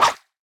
brush3.ogg